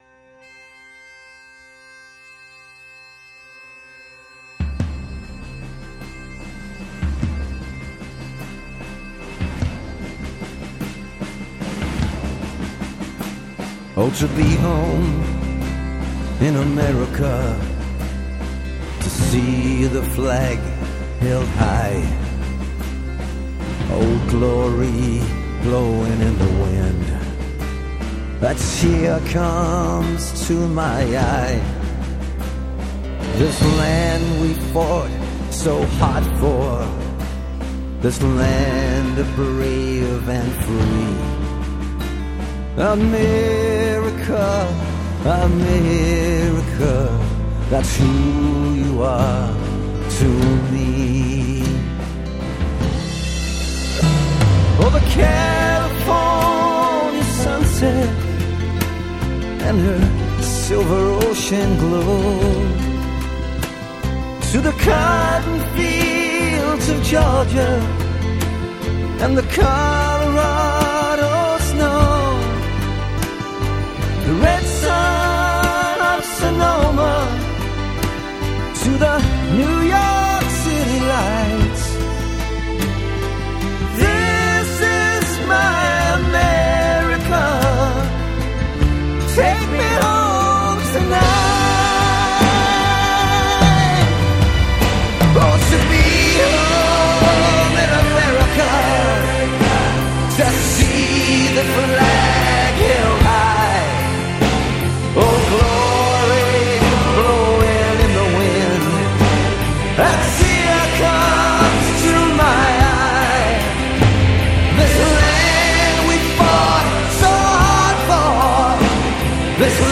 records as background in this tribute song to the troops
Bagpipes